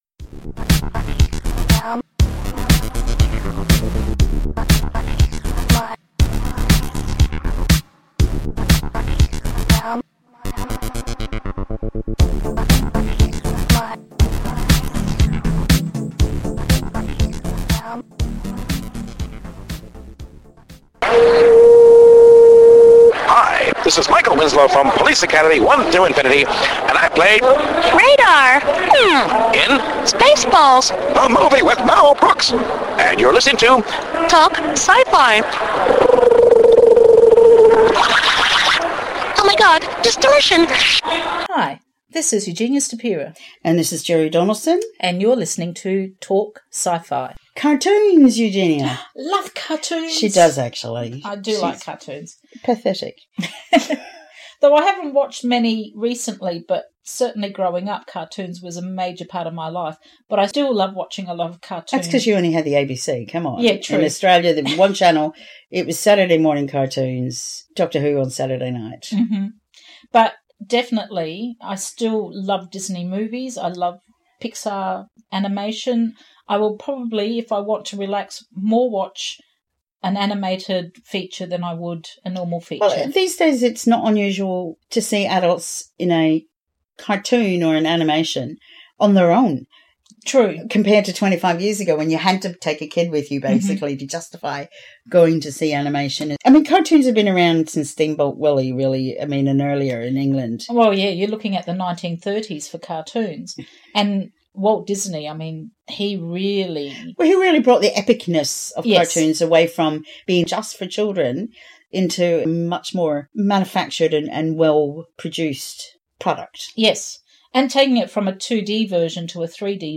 Animation is a genre for everyone. In this episode we talk to an amazing voice actor, Rodger Bumpass, better known by his alternate name of Squidward from Spongebob Squarepants!